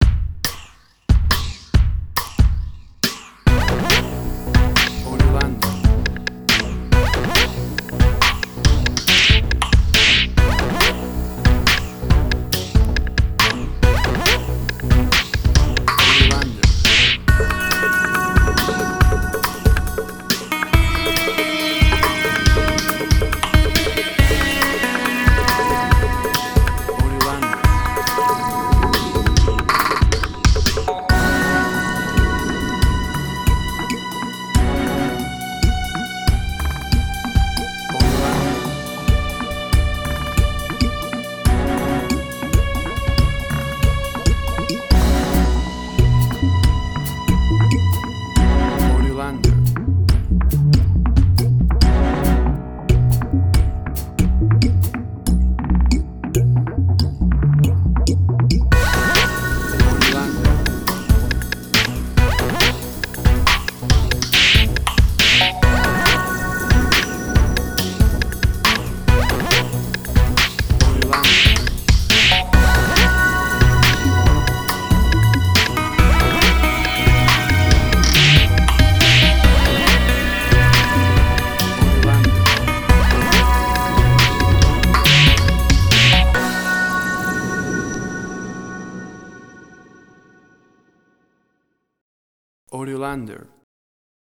Suspense, Drama, Quirky, Emotional.
WAV Sample Rate: 16-Bit stereo, 44.1 kHz
Tempo (BPM): 139